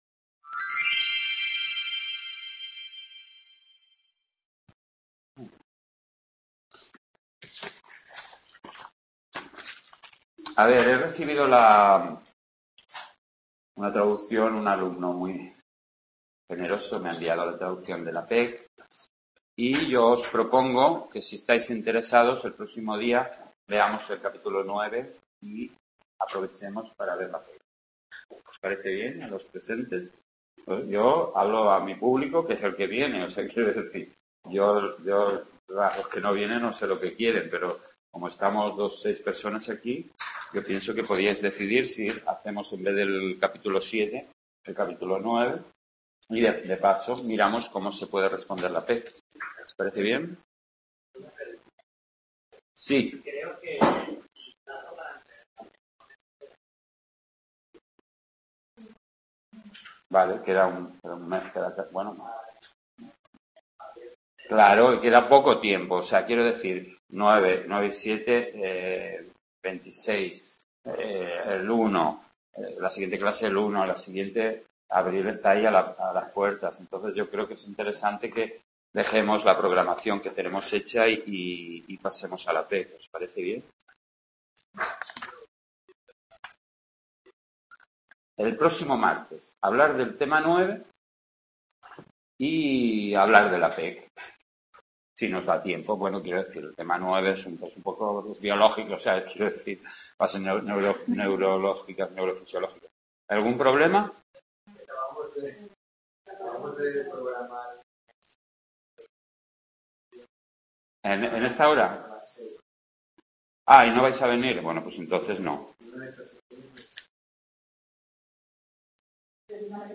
Tema 6 de Psicología del Pensamiento, realizada en el Aula de Sant Boi